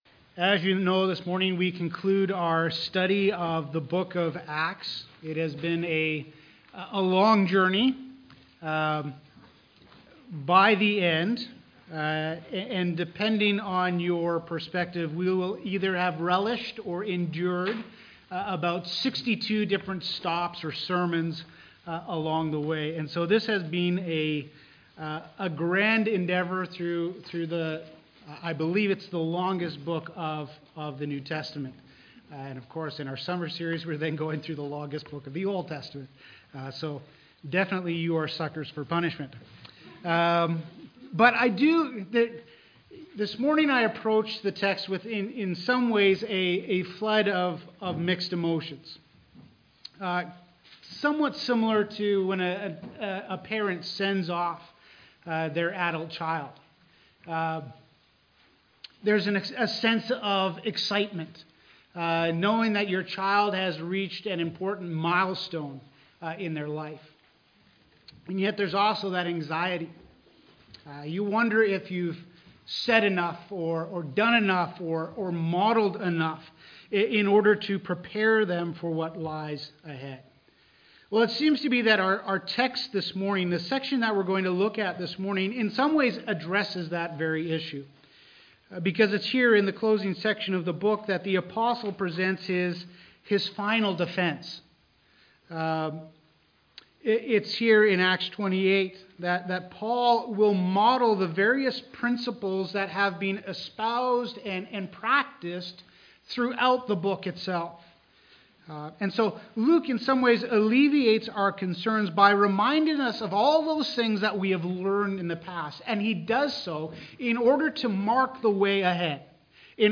Acts Sermon Series